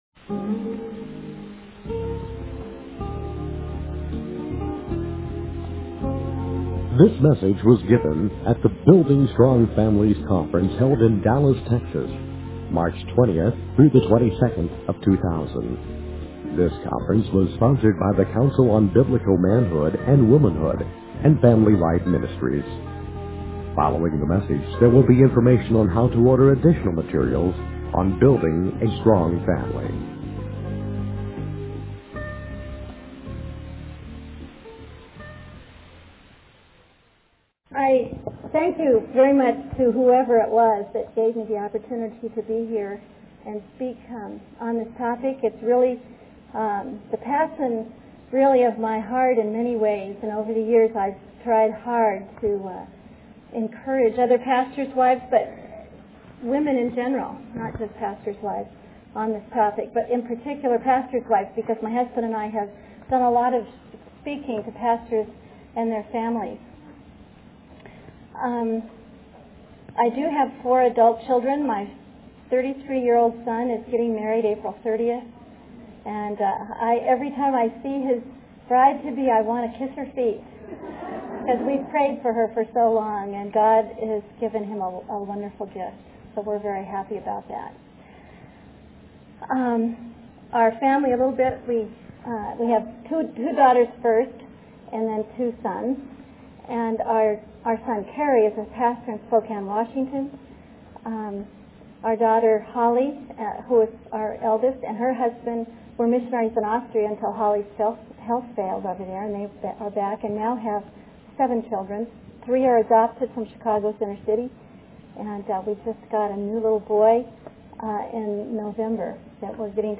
In this sermon, the speaker emphasizes the importance of understanding the true gospel and not just relying on a one-time prayer for salvation.